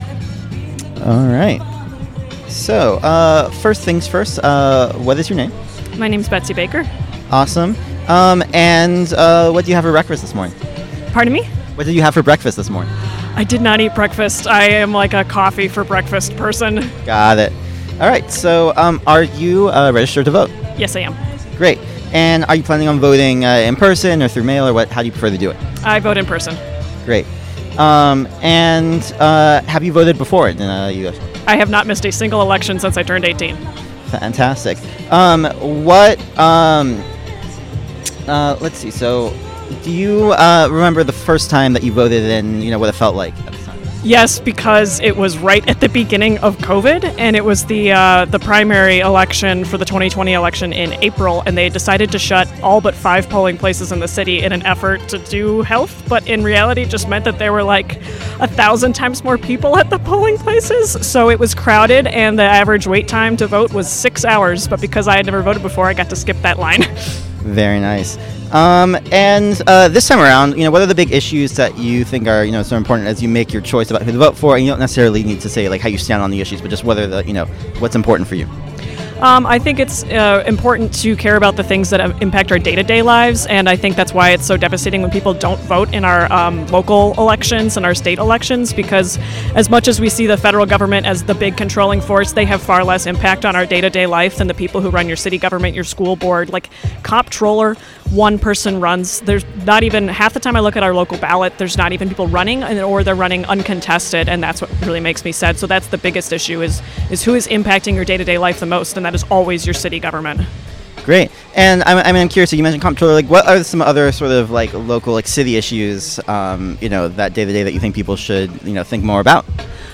Wisco Soundoff Day 1